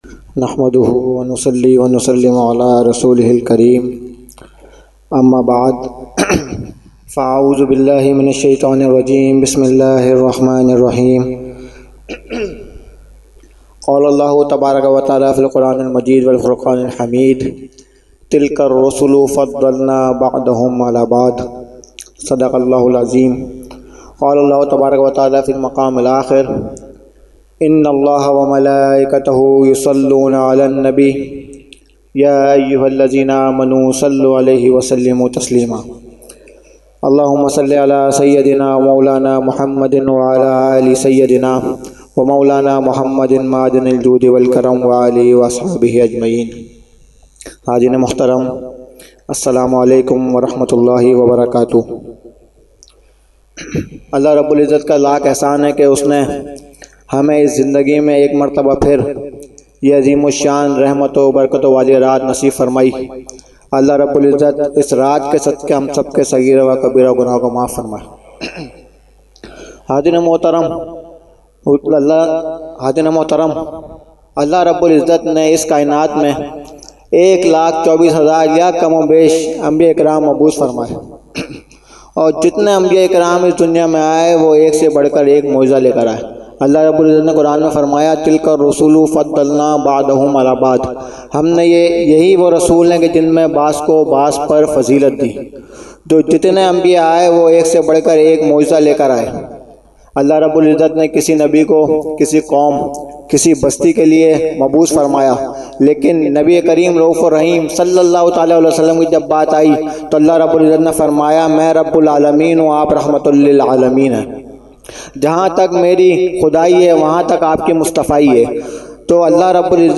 Jashne Subhe Baharan held 29 October 2020 at Dargah Alia Ashrafia Ashrafabad Firdous Colony Gulbahar Karachi.
Category : Speech | Language : UrduEvent : Jashne Subah Baharan 2020